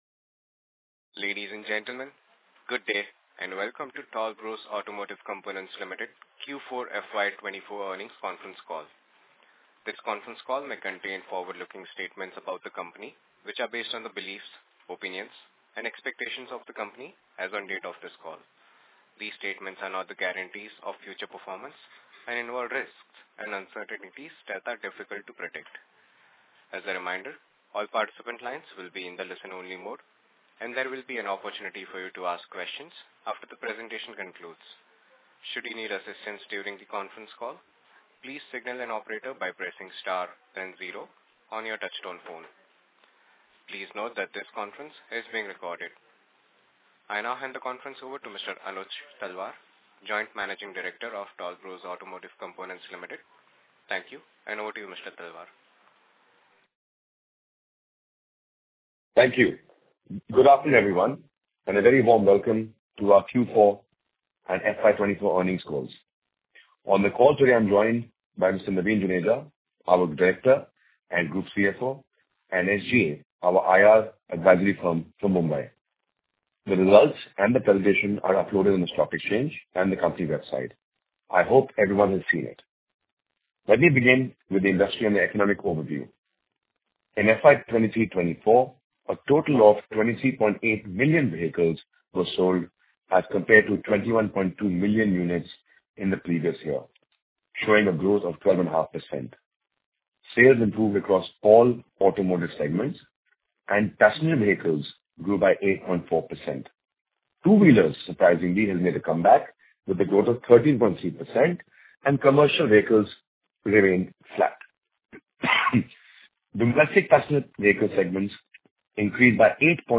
Q3 & 9M FY23 Earning Call Audio Recording